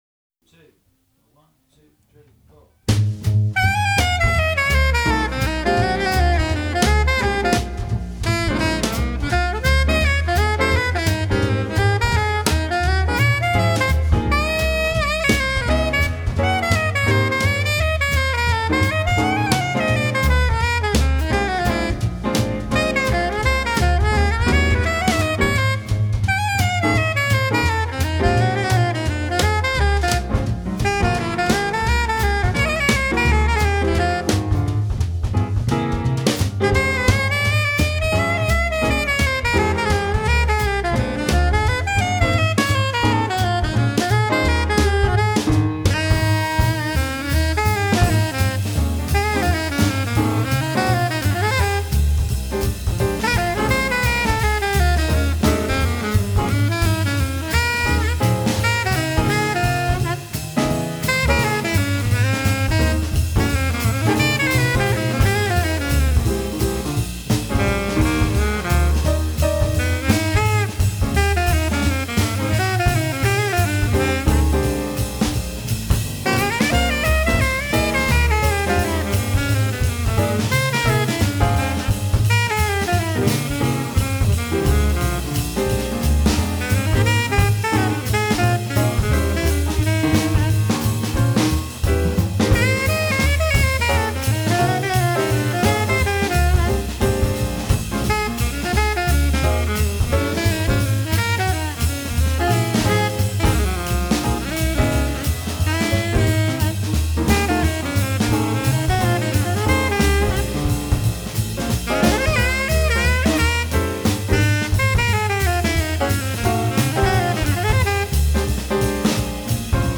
Donna Lee medium tempo -下载地址列表-乐器学习网
0118-Donna+Lee+medium+tempo.mp3